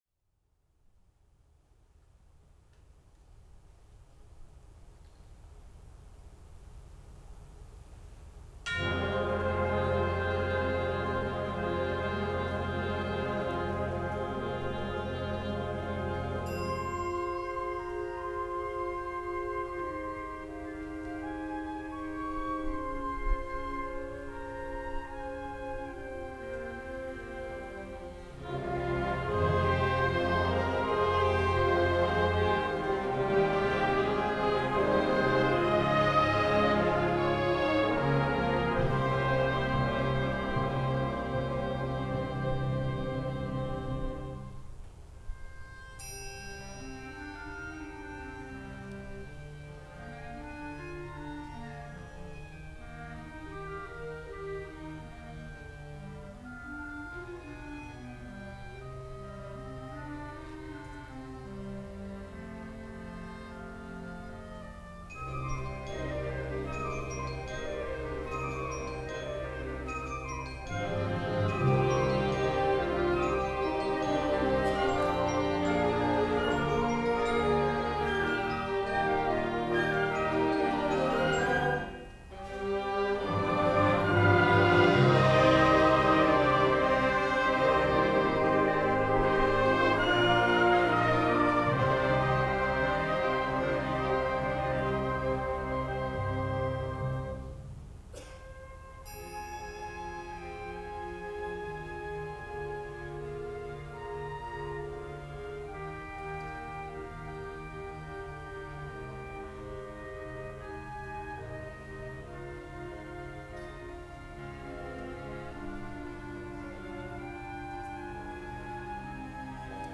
The LBB's winter concert was on Sunday March 6th 2022 at the Lexington High School in Lexington.